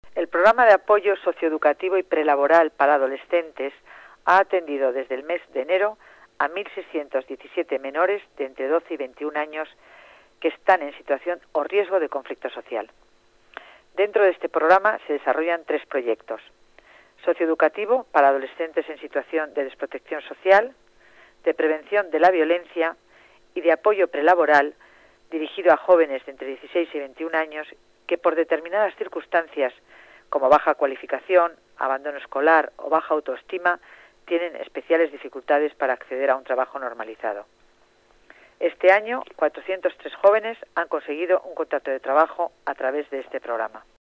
Nueva ventana:Declaraciones de Concepción Dancausa, delegada de Familia y Servicios Sociales